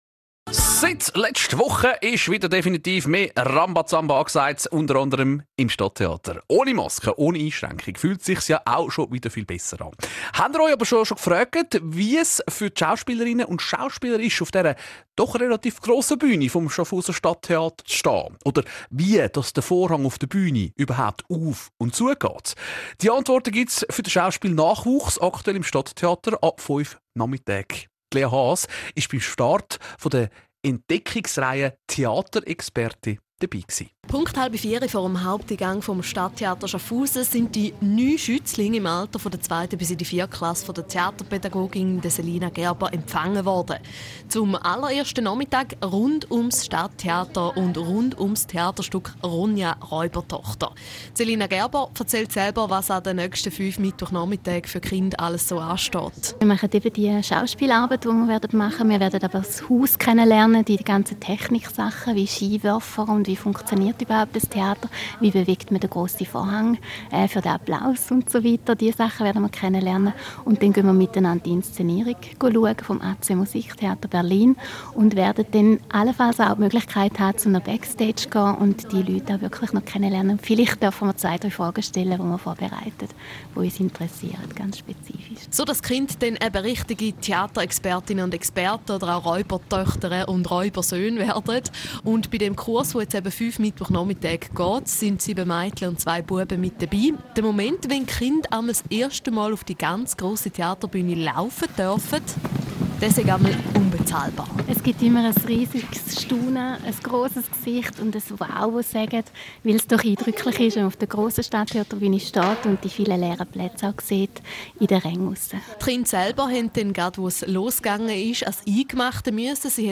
2022 Interview Radio Munot